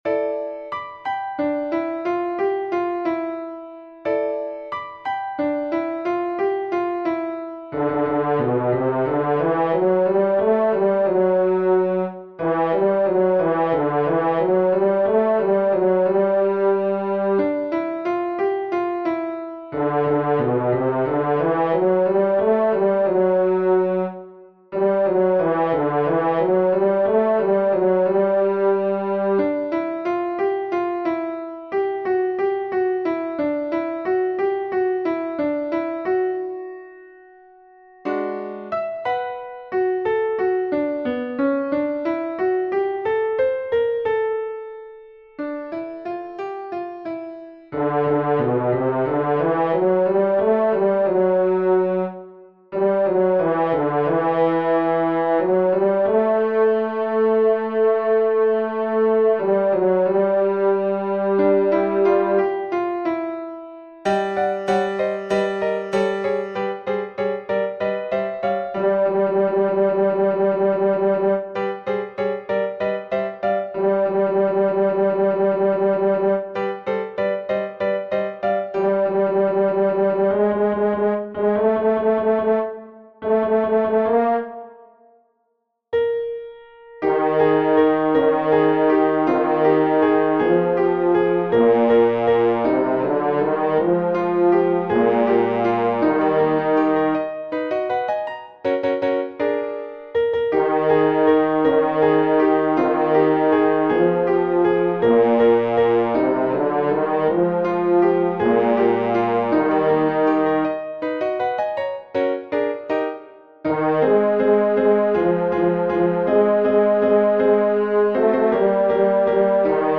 Voice part practice (up to Letter I, page 11):
The featured voice is a horn.
BASS 2